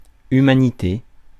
Ääntäminen
France: IPA: [y.ma.ni.te]